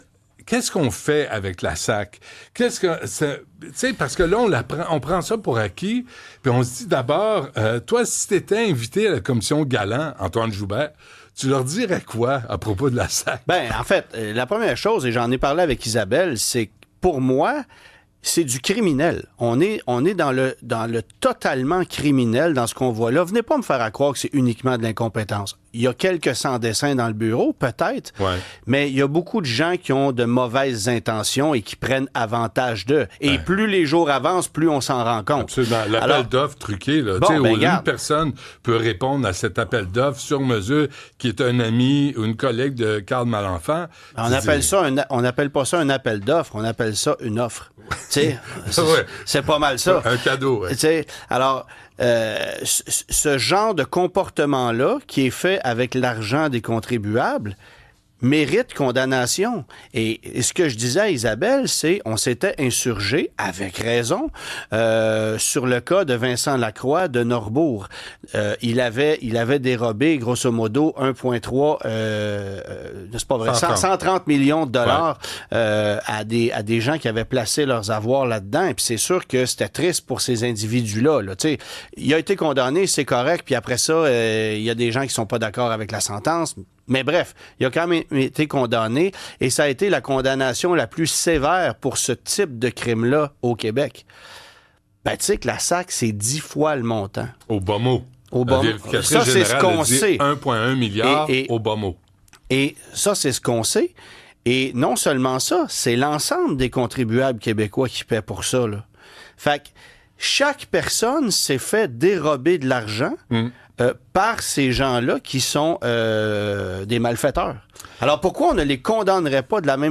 Voici un extrait audio d'une entrevue complète disponible sur YouTube, 23.59 min.